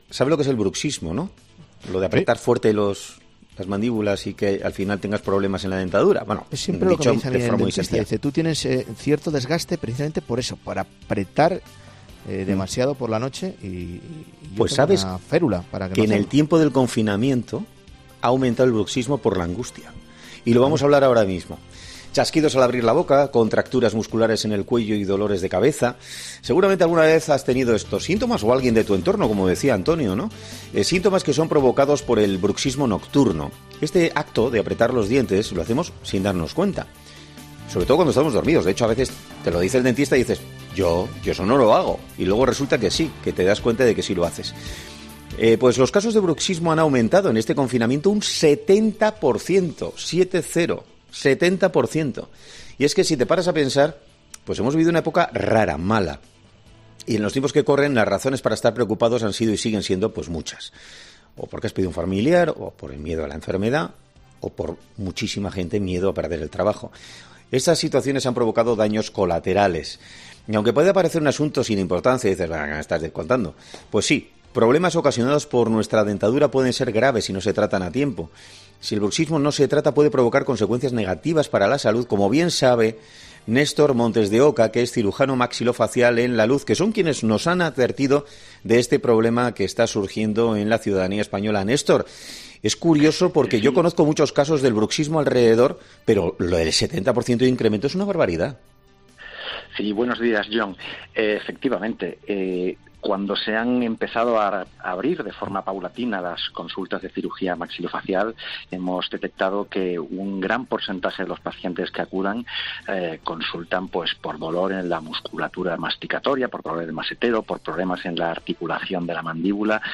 Hoy en 'Herrera en COPE' hemos hablado con el cirujano